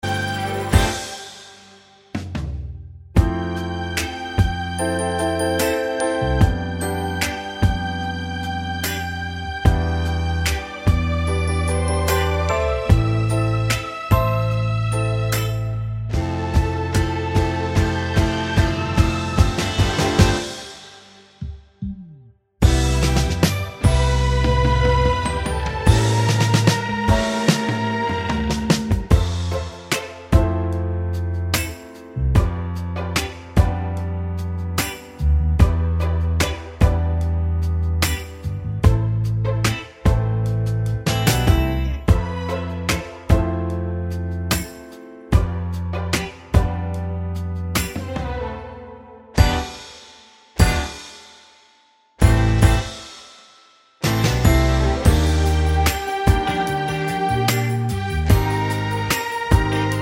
no Backing Vocals Soul / Motown 4:07 Buy £1.50